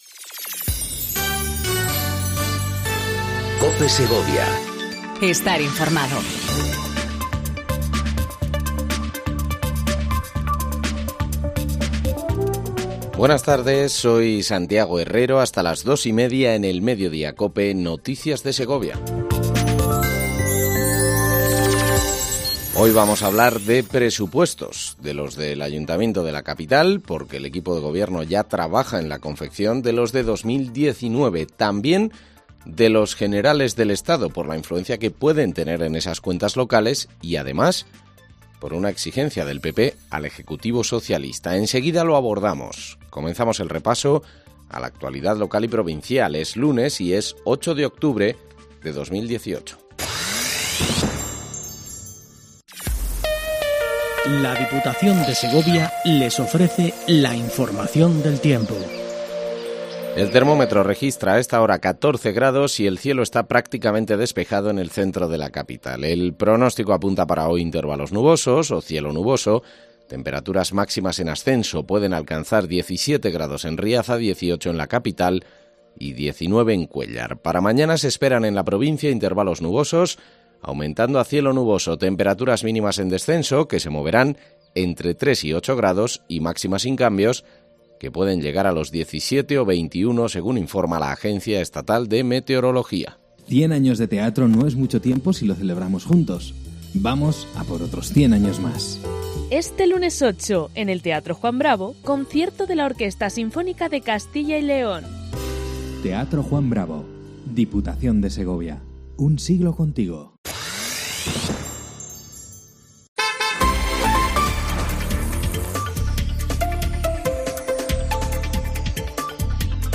INFORMATIVO MEDIODÍA EN COPE SEGOVIA 14:20 DEL 08/10/18